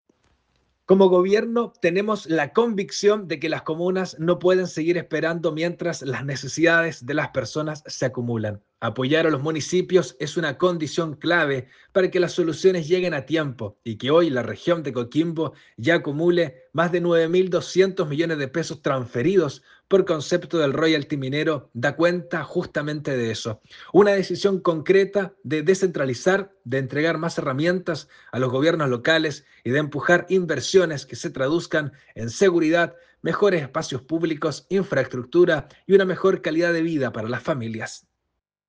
En ese contexto, el seremi de Gobierno, Darwin Cortés, sostuvo que fortalecer a los municipios no solo es una señal de respaldo a la gestión local, sino también una condición necesaria para que las respuestas del Estado lleguen con mayor oportunidad a los territorios.
Seremi-de-Gobierno-Darwin-Cortes-online-audio-converter.com_.mp3